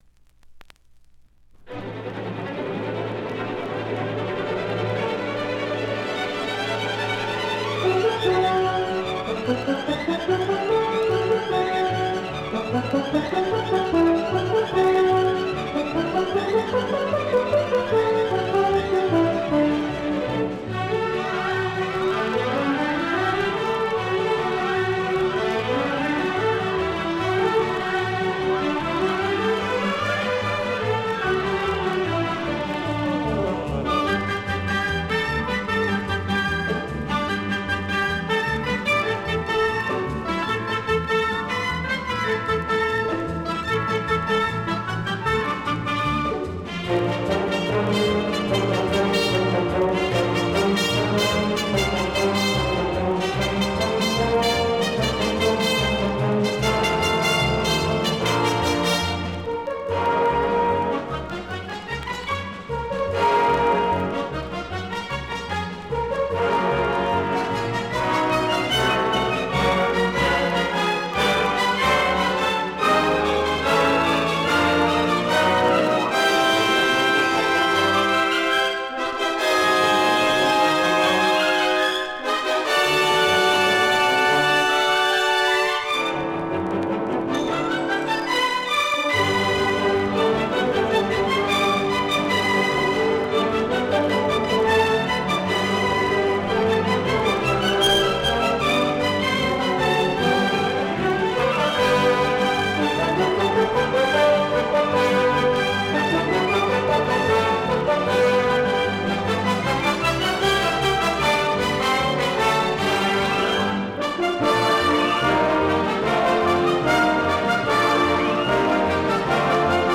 Рефрен отсутствует